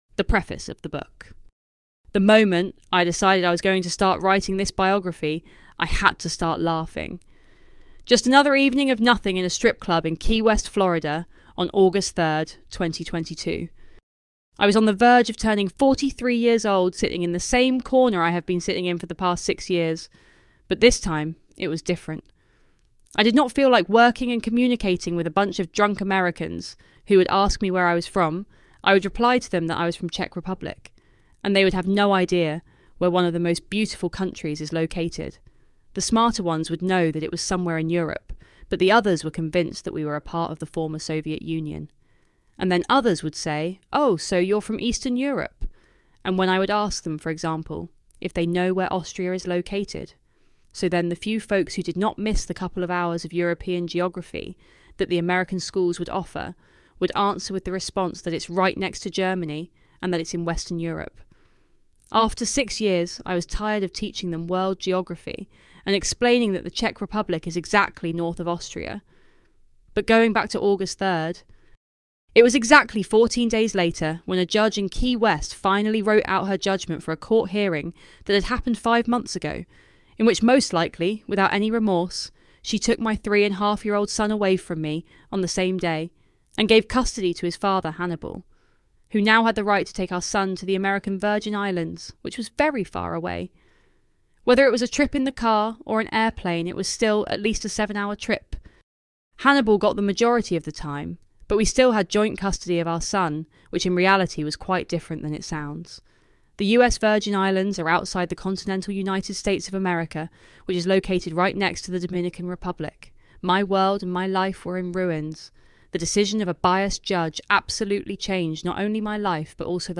The Life Story of a Stripper (EN) – audiobook – $24.99
Sample from the introduction of the book